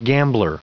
Prononciation du mot gambler en anglais (fichier audio)
Prononciation du mot : gambler